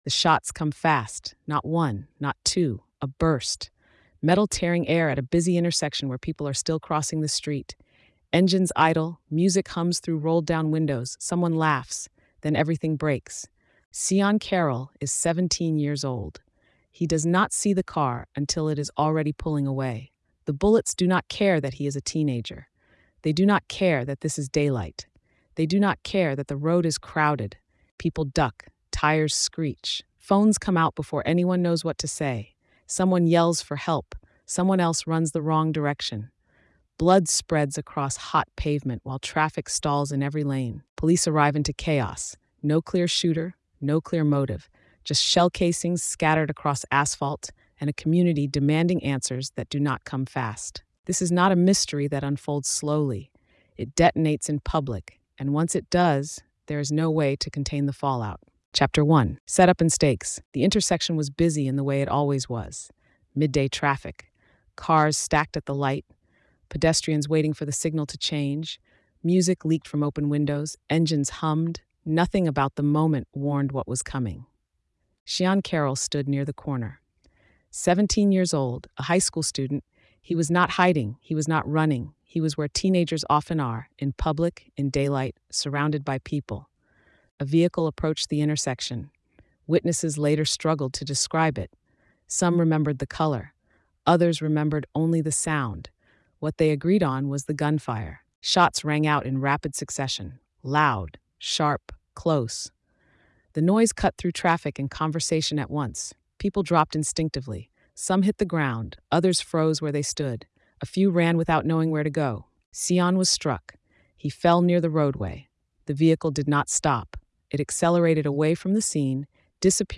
Told in a forensic, grounded voice, the series follows the crime from the moment gunfire erupts through the investigation’s most critical turns, revealing how public violence, limited evidence, and unanswered questions collide in a case that remains unresolved.